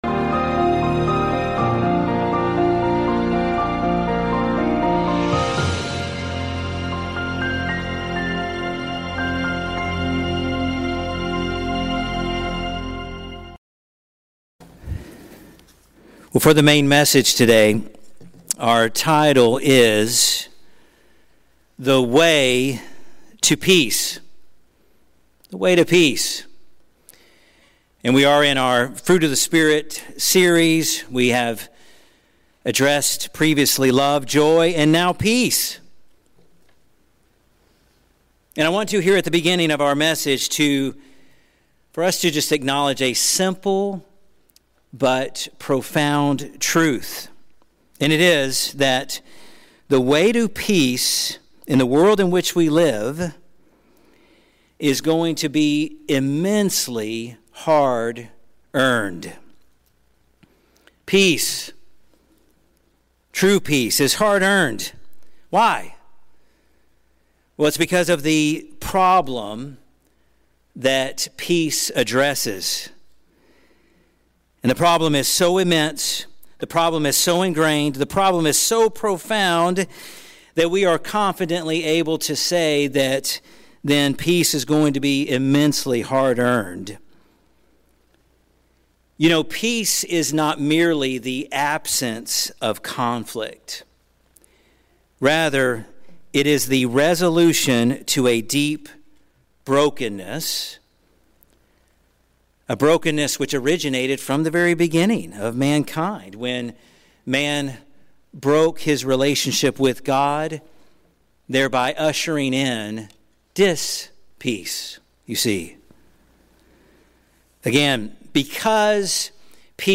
This sermon teaches that true peace is not the absence of conflict but the restoration of humanity’s broken relationship with God, a peace that can only be found through Jesus Christ. Jesus reveals that the way to peace is hard-earned, involving purifying fire, costly sacrifice, and even division, as hearts and loyalties are transformed.